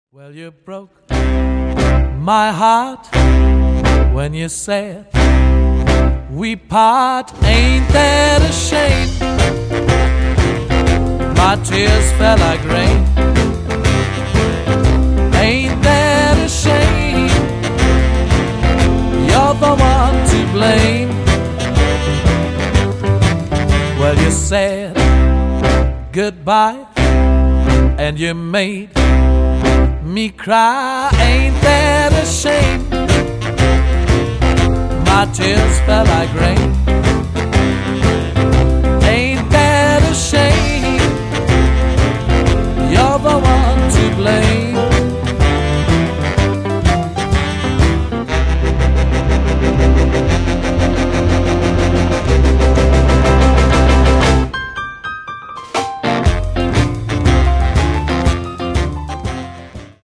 cajon & drums